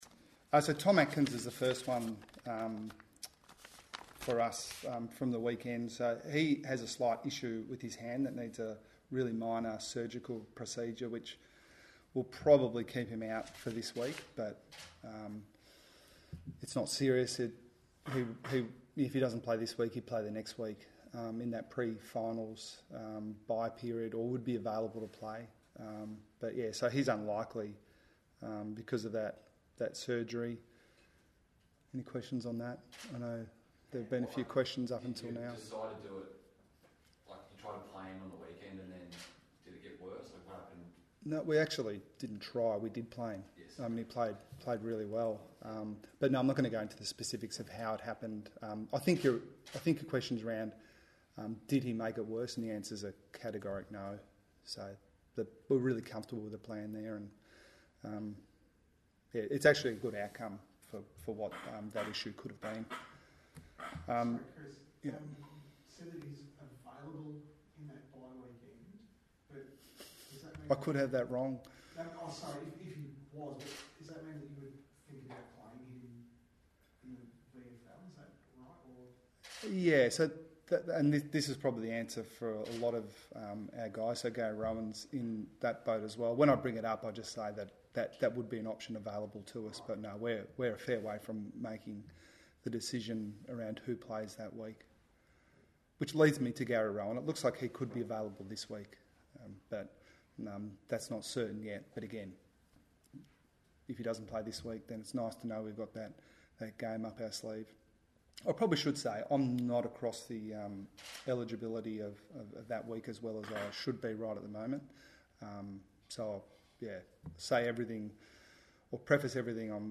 Geelong coach Chris Scott faced the media ahead of Saturday's clash with Carlton.